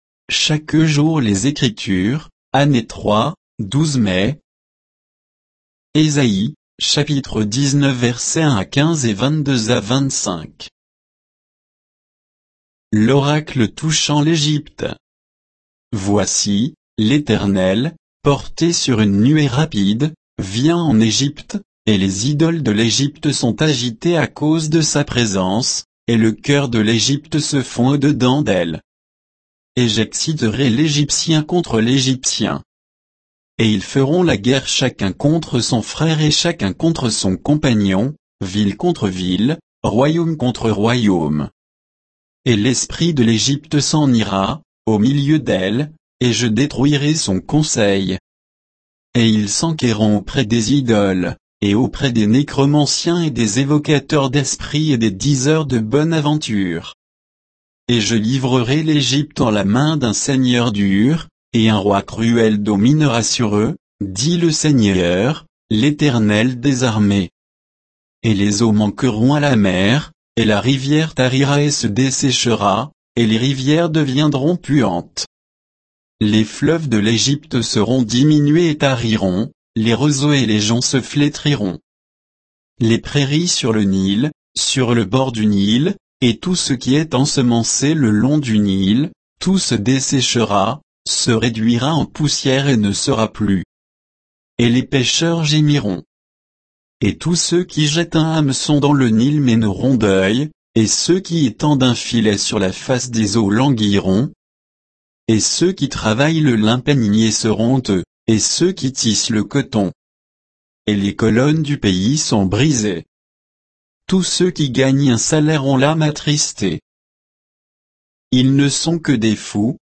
Méditation quoditienne de Chaque jour les Écritures sur Ésaïe 19